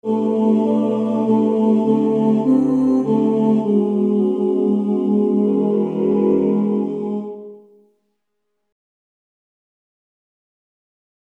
Key written in: E Major